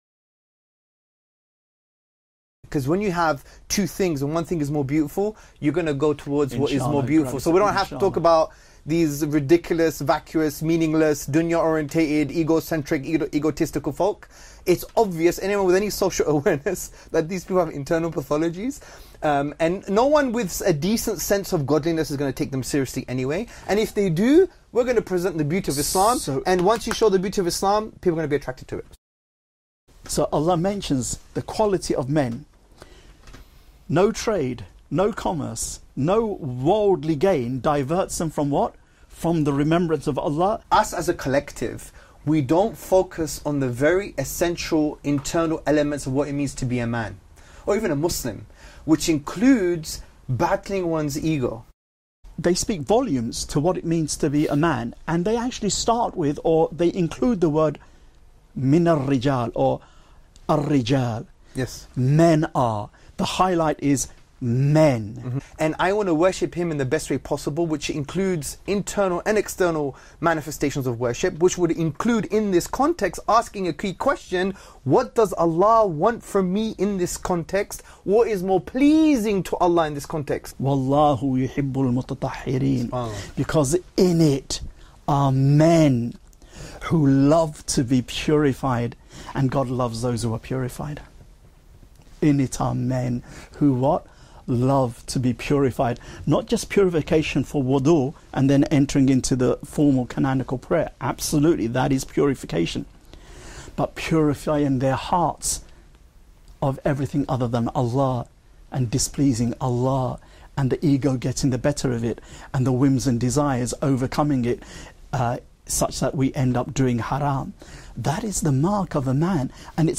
Islamic Masculinity (rujulah)： A Conversation on Manliness & Exploring 4 Relevant Qur'ānic Verses.mp3